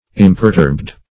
Imperturbed \Im`per*turbed"\, a.